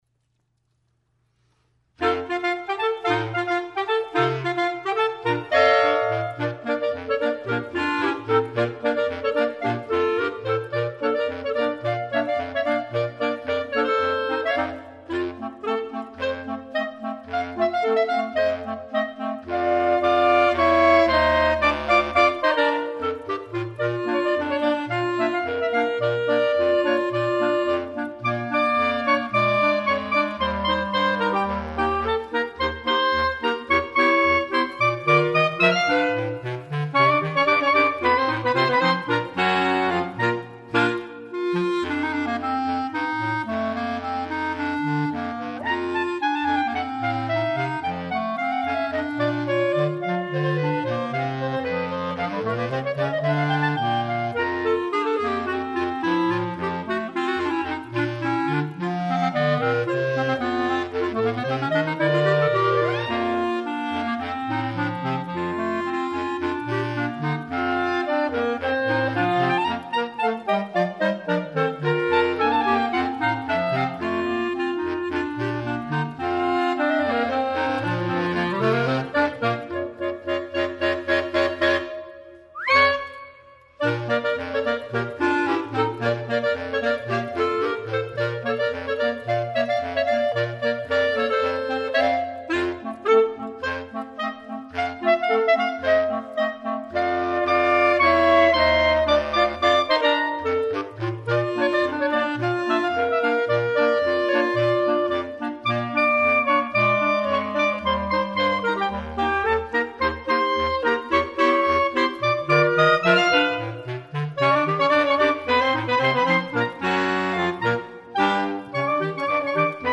per quintetto di clarinetti